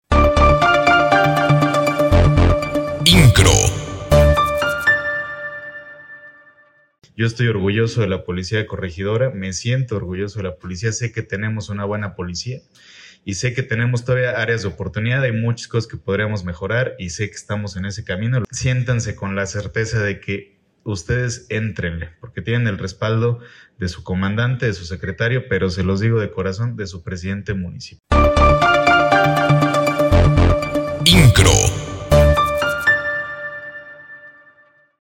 Lugar: Secretaría de Seguridad Pública Municipal de Corregidora
• Chepe Guerrero, Presidente Municipal de Corregidora.